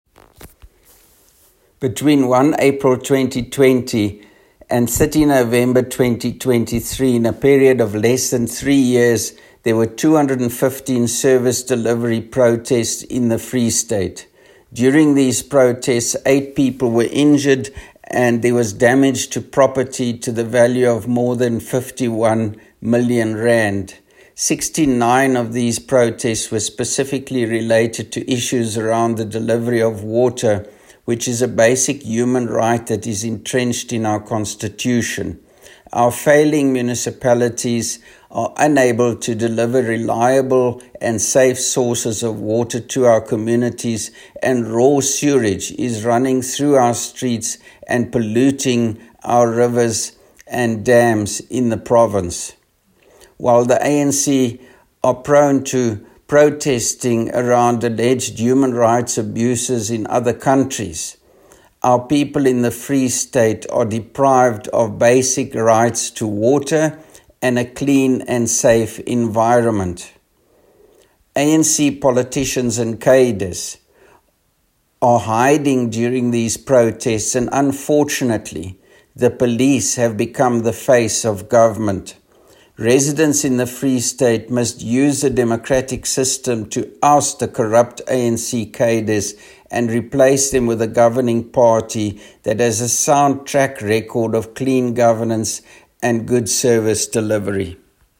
Afrikaans soundbites by Roy Jankielsohn MPL